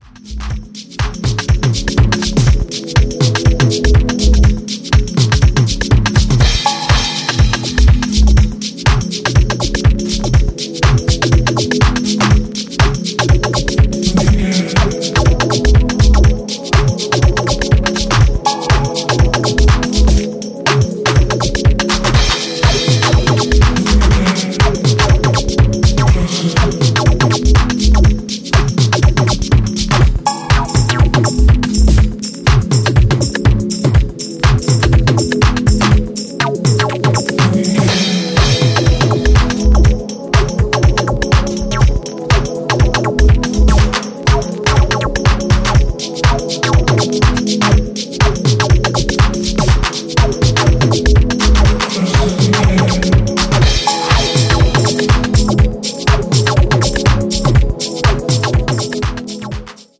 Minimalistics touched with melancholy.
House Techno